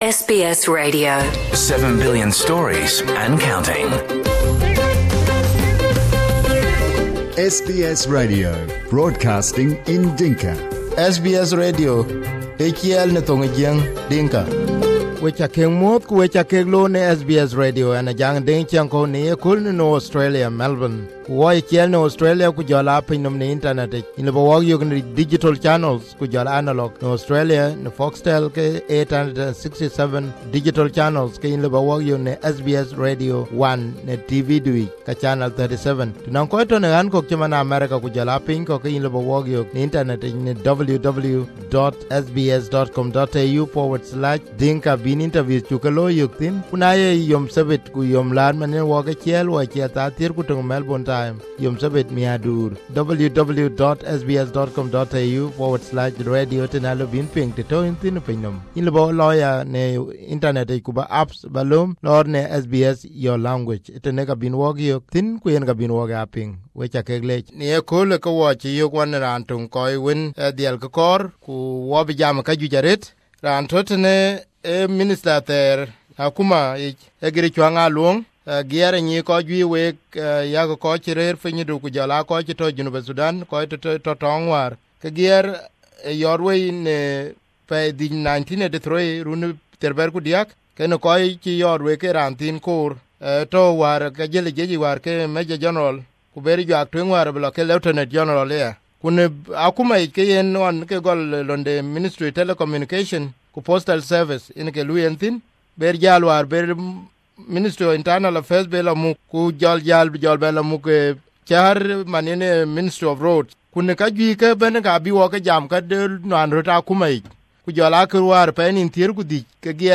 My first interview with South Sudan former Minister Gier Chuang Aluong
First time at SBS Dinka Radio we had very exclusive interview with Gier Chuang Aluong the former Minister in the Government of Republic of South Sudan.